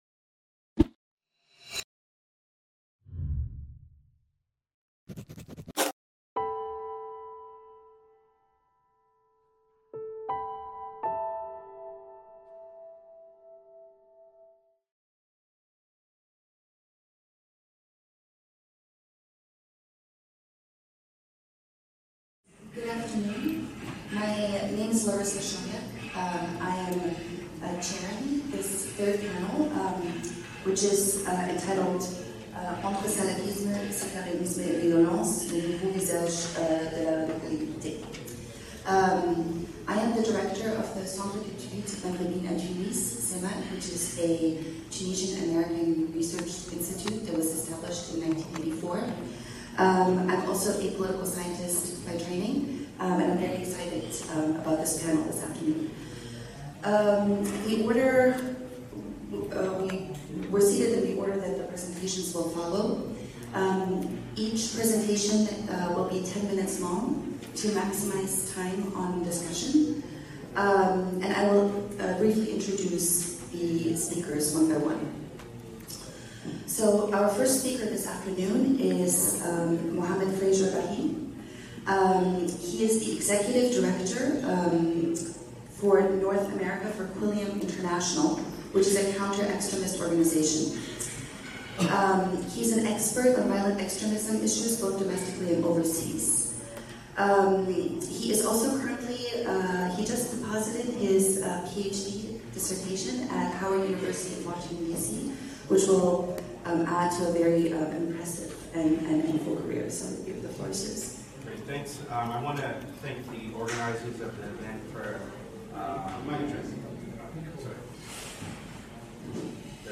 Entre salafisme, sectarisme et violence : les nouveaux visages de la radicalité - IPEV - Colloque de Tunis | Canal U
Le Panel International sur la Sortie de la Violence s’est réuni à Tunis le 3 et 4 Juillet 2017.